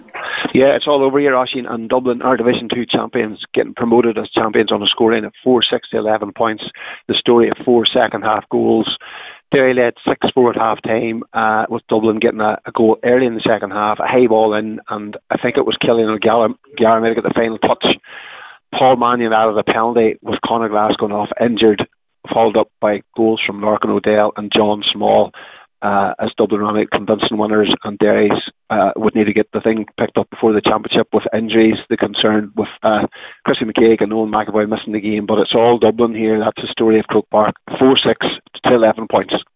has the full time report…